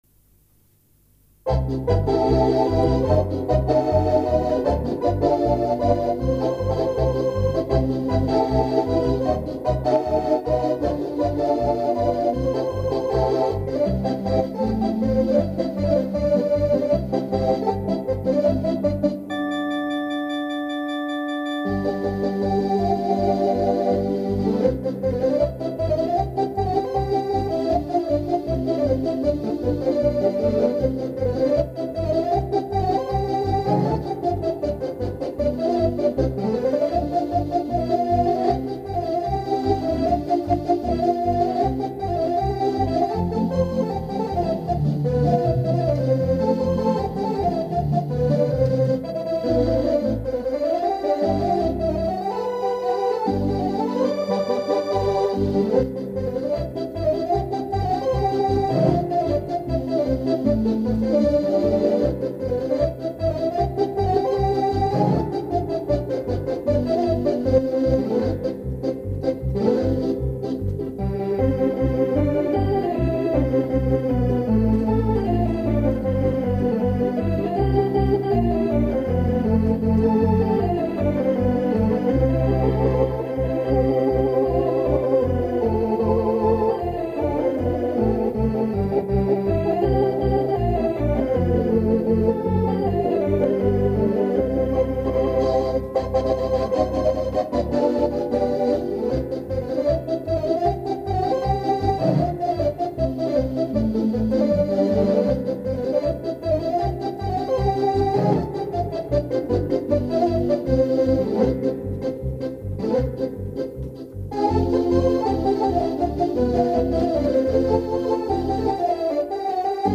Orgue Wurlitzer 4573
Voici une photo de mon orgue Wurlitzer modèle 4573 de 1972, c'est un instrument a transistors, équipé d'un Hp a aube tournante, et d'un synthétiseur (3eme claviers).